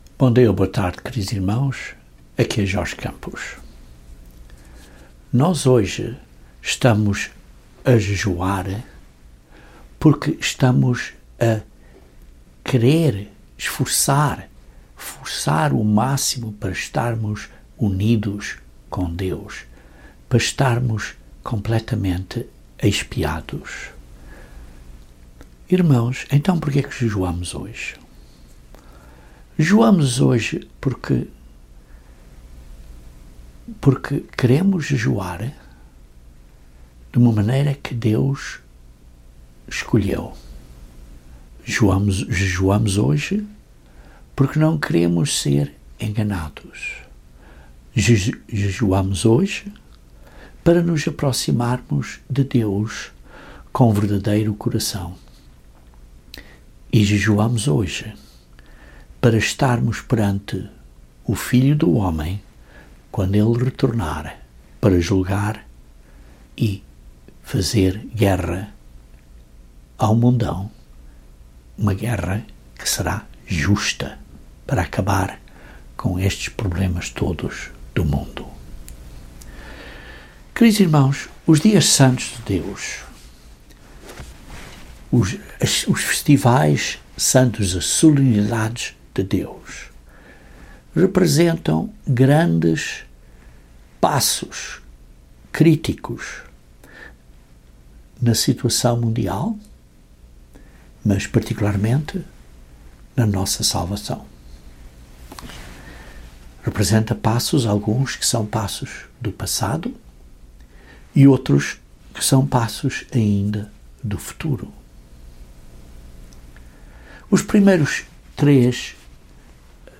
A quinta solenidade, o Dia de Expiação tem um simbolismo muito importante. Este sermão aborda alguns pontos simbólicos do Dia de Expiação usando o jejum como uma base para os explicar.